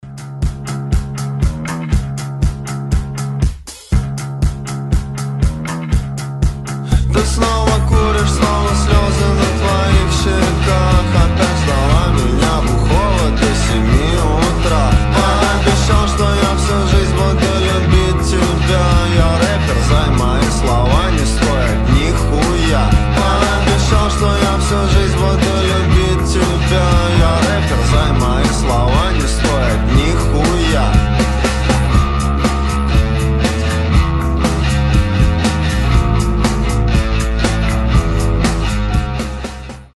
• Качество: 320, Stereo
Rap-rock
Alternative Rap